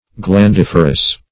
Search Result for " glandiferous" : The Collaborative International Dictionary of English v.0.48: Glandiferous \Glan*dif"er*ous\, a. [L. glandifer; glans, glandis, acorn + ferre to bear; cf. F. glandif[`e]re.]